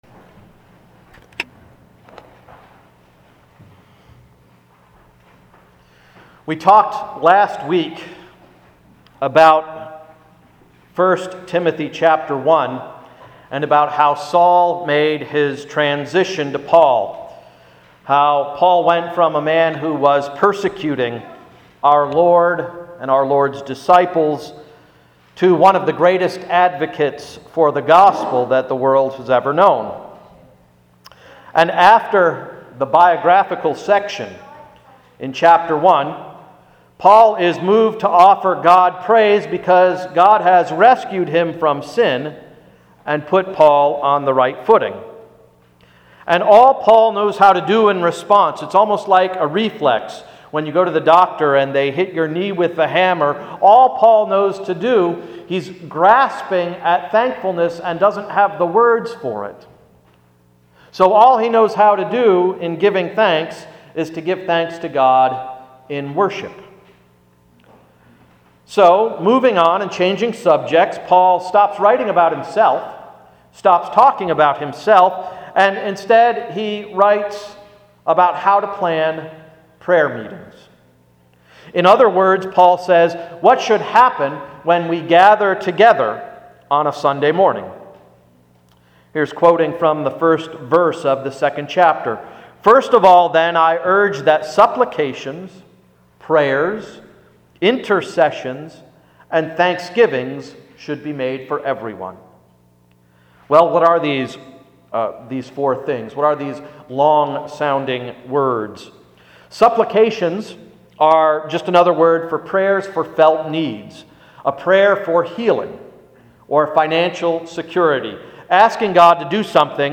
“Planning Prayer Meetings”–Sermon of September 19, 2010
A reading from 1 Timothy 2:1-7: